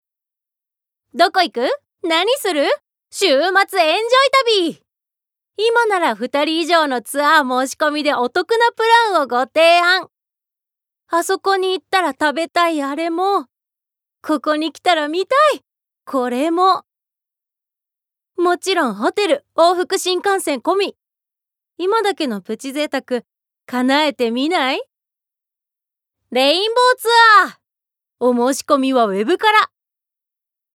ボイスサンプル
ナレーション２